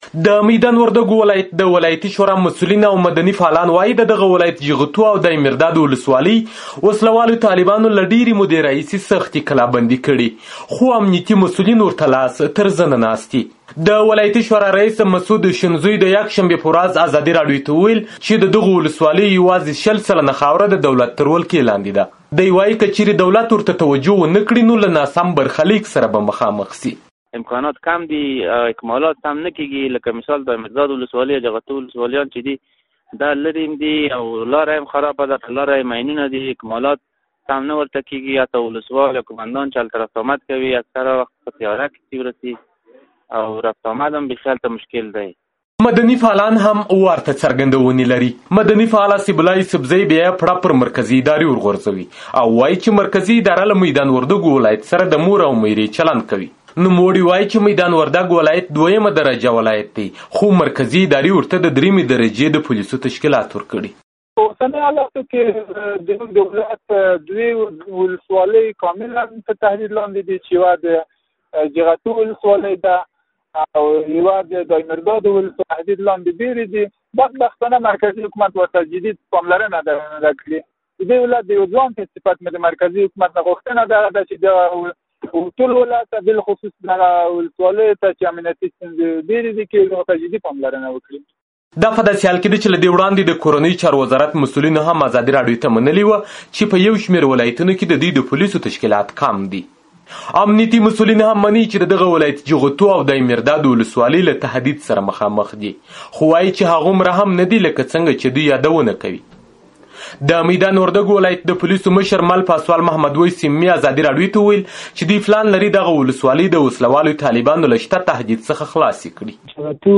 د میدان وردګ راپور